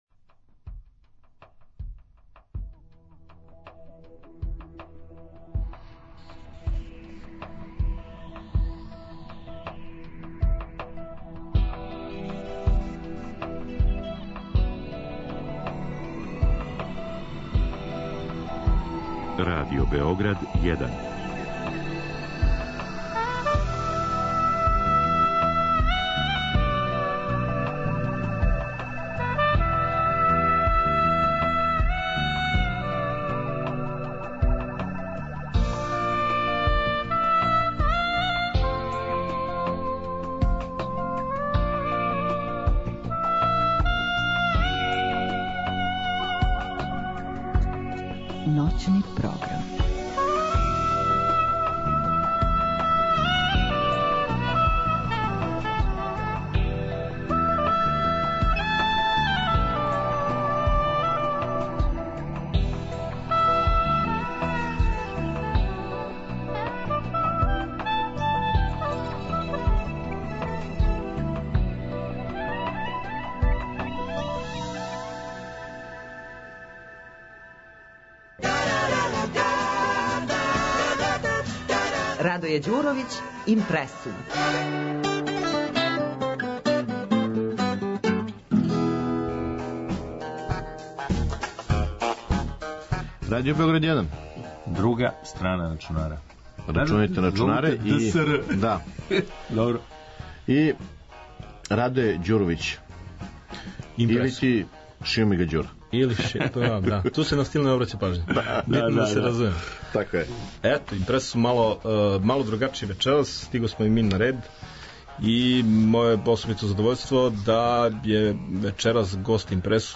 "Doktori za informatiku" bila je tema rubrike IMPRE§UM emisije "Druga strana računara", noćnog programa Radio BGD1 emitovanog 10. Maja 2014.godine.